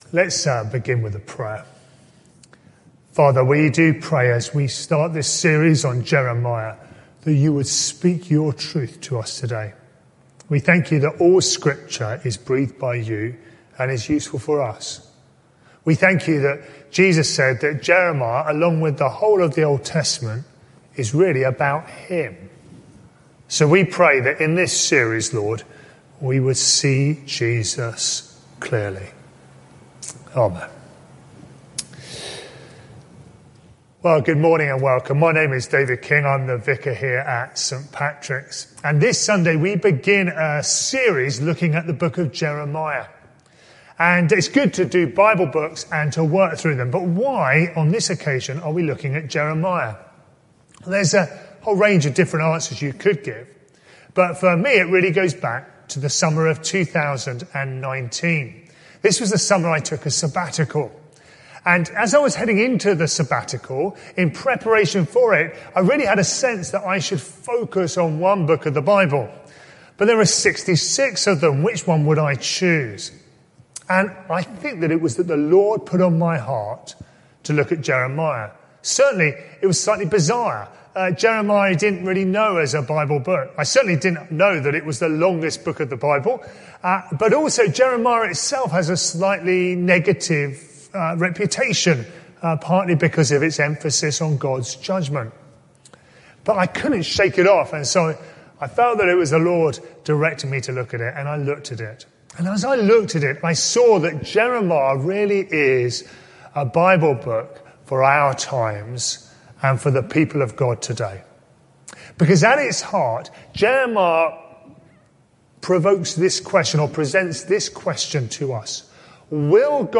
This sermon is part of a series: 21 February 2021